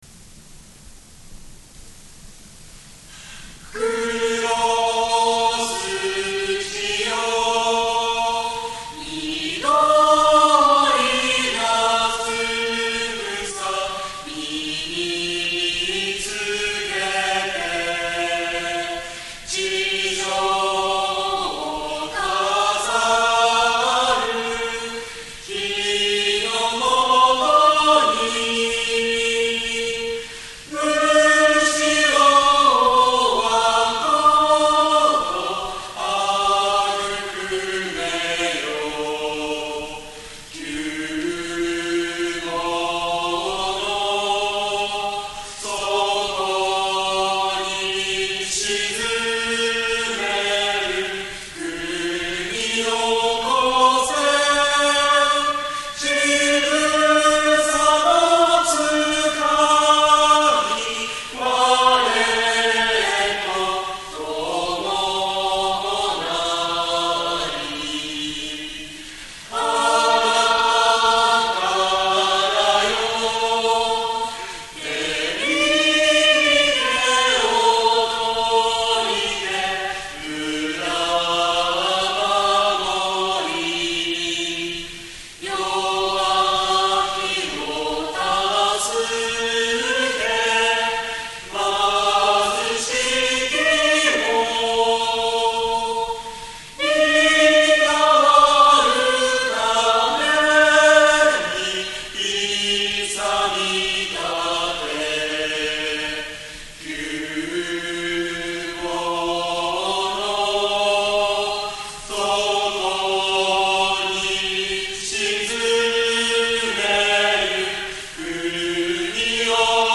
フルコーラスユニゾン 歌(MP3) 酪農学園大学・酪農学園大学短期大学部合唱団 2002年6月18日 黒澤記念講堂にて収録